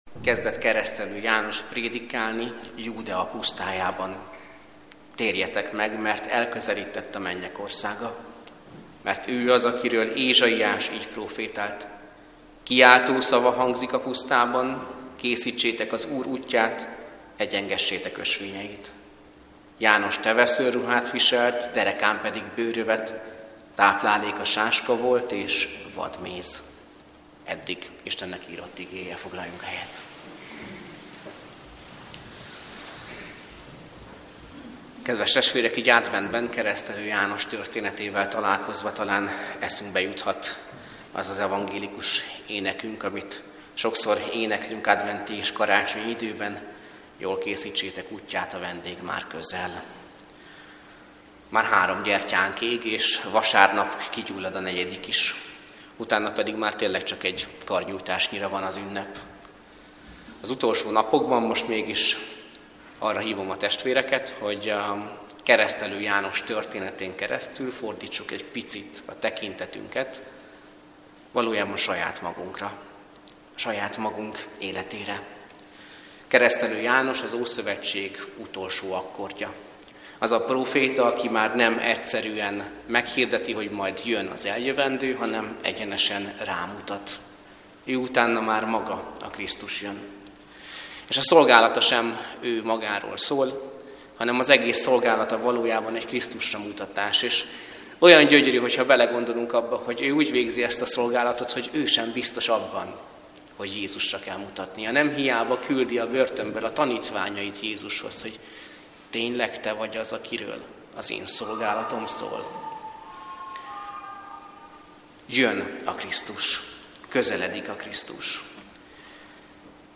Advent esti áhítat 3. - Ó, mily szép és mily gyönyörűséges, ha a testvérek egyetértésben élnek!